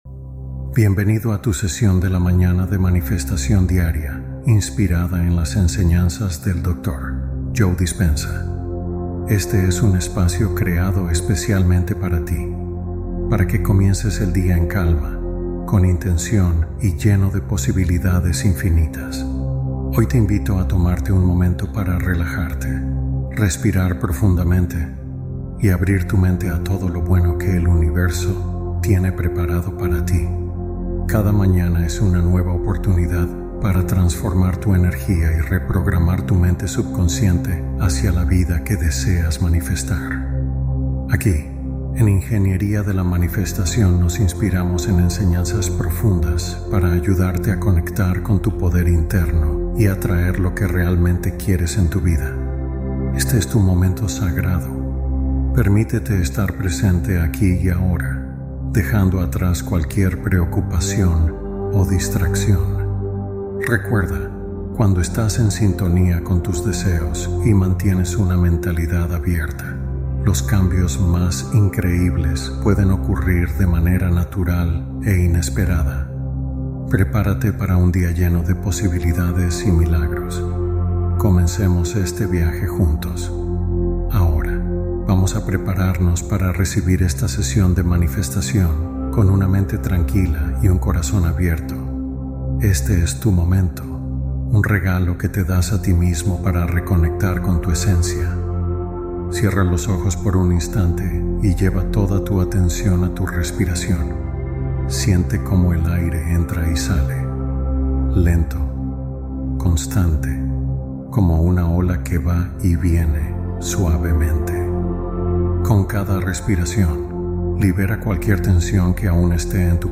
Meditación Matutina Para Iniciar el Día con Milagros